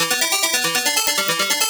CS_FMArp C_140-E.wav